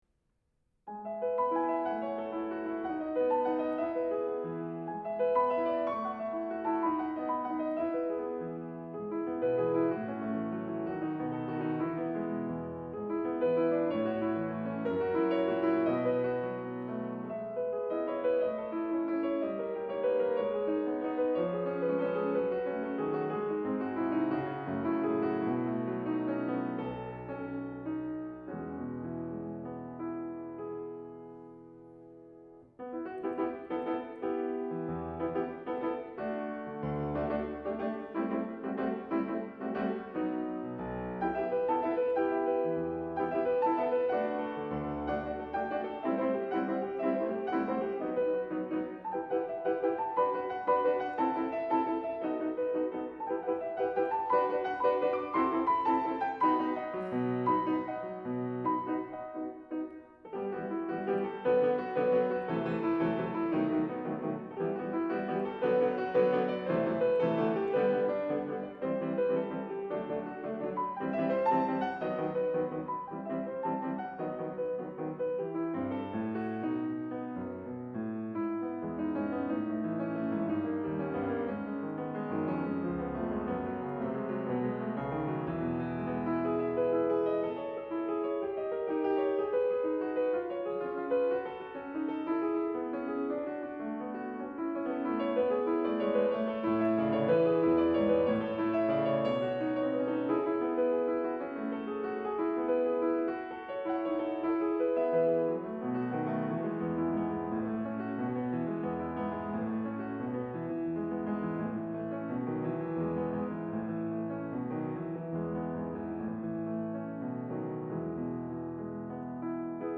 去る11月2日に日本スペインピアノ音楽学会の主催により行われた『第16回スペインピアノ作品を弾く会』での自分の演奏の録音を学会の方から頂きました（ありがとうございました）。演奏したのはベネズエラの作曲家モデスタ・ボルのピアノ曲《クリオージョ組曲》で、３曲から成る組曲で 1. 前奏曲-ポロ、2. 歌、3. 舞曲です。相変わらず音がすっぽ抜けた所があったりとアマチュア丸出しですが、曲の抑揚とか表情感とかはまあまあ出来たかなぁと一人悦に入ってます。